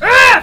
Worms speechbanks
ow2.wav